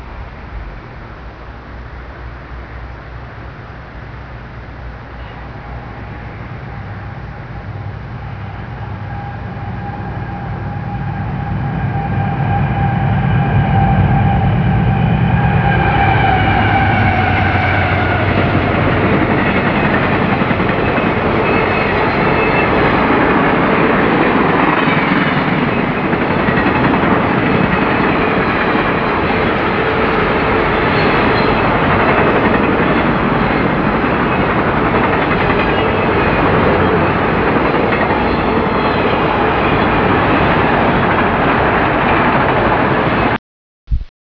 - Erie, Pennsylvania
A pair of CSX AC60CW's rip past the GE plant